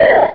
Cri de Makuhita dans Pokémon Rubis et Saphir.